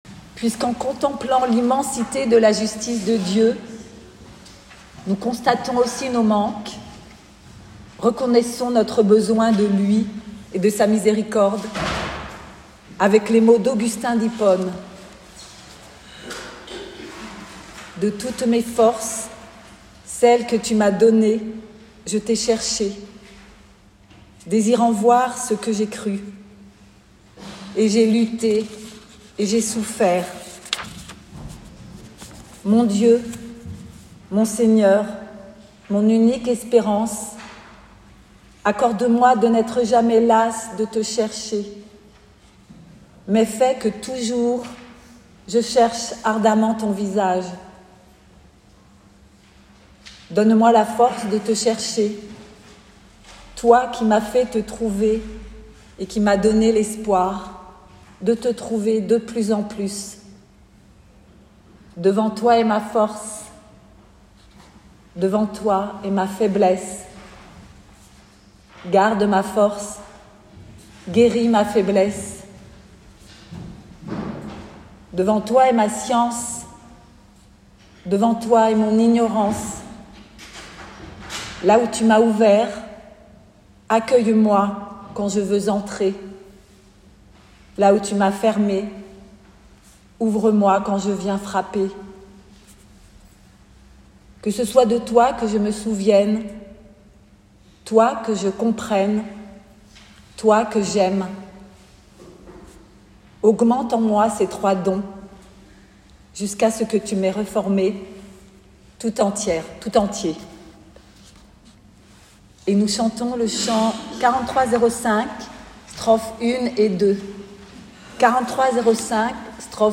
" je vous donne un commandement nouveau" Culte du 18 mai 2025